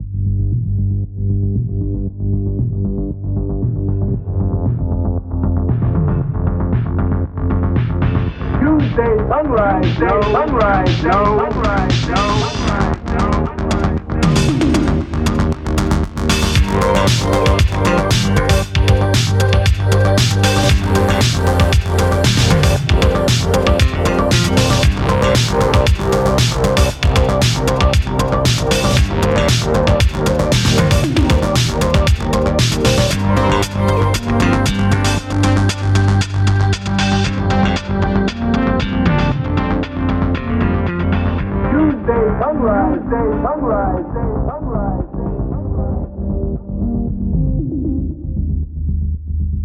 A short, dynamic electronic song. It opens with an echoing voice proclaiming that "Tuesday's sunrise knows.".